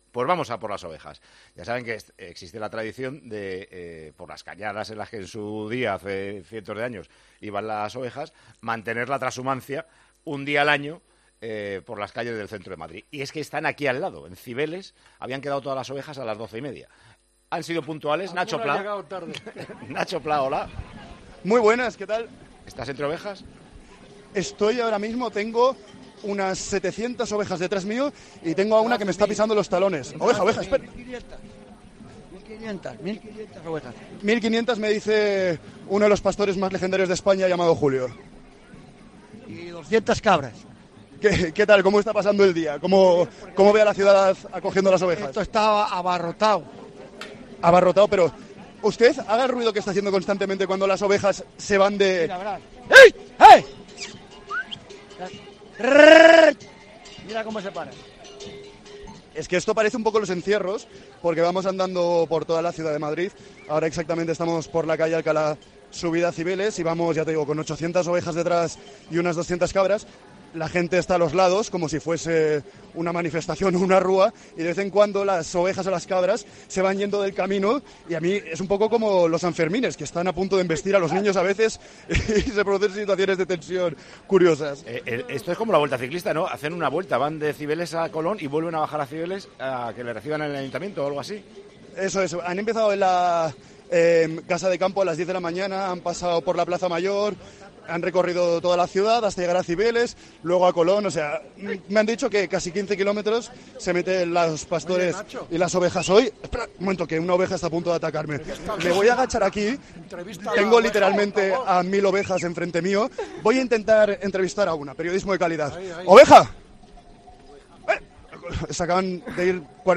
Como todos los años cientos de ovejas y cabras cruzan las calles de Madrid en la Fiesta de la Trashumancia. Tiempo de Juego se ha colado en la edición de 2022.